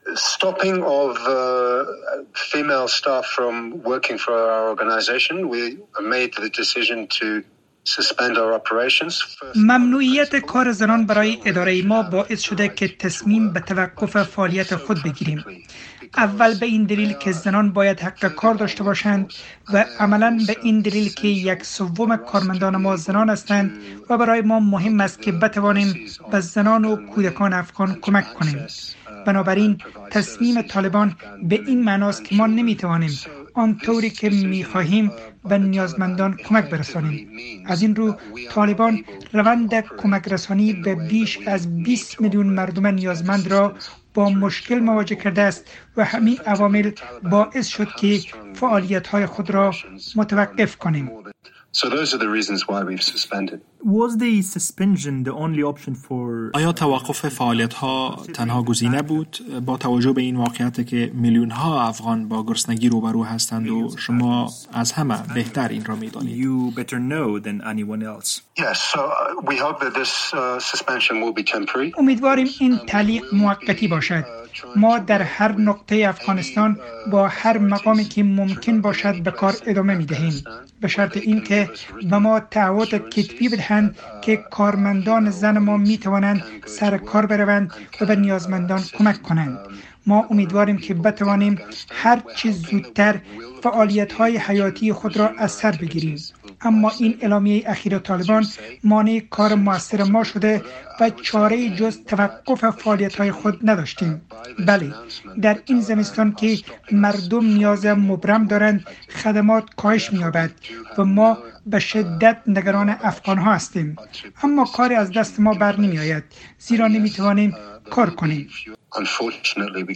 مصاحبه با رئیس شورای پناهندگان ناروی در مورد تعلیق فعالیت‌های آن در افغانستان